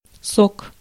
Ääntäminen
IPA : /ˈsiːmən/